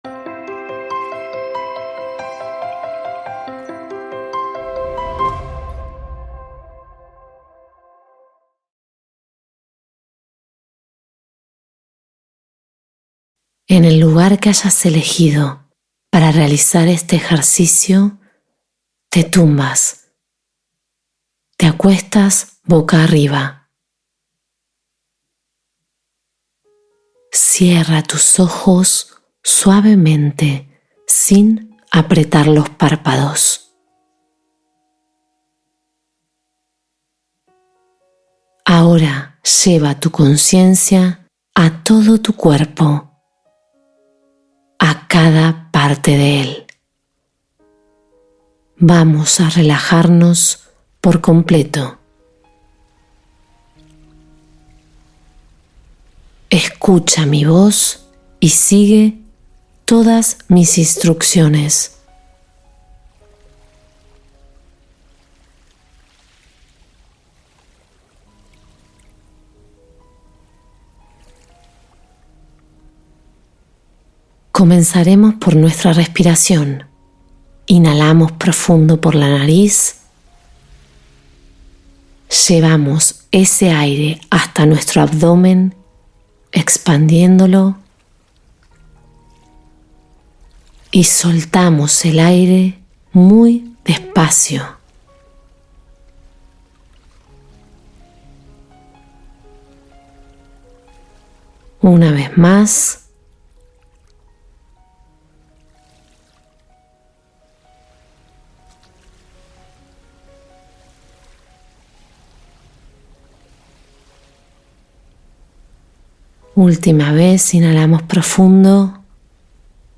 Meditación para dormir profundamente y despertar renovado al día siguiente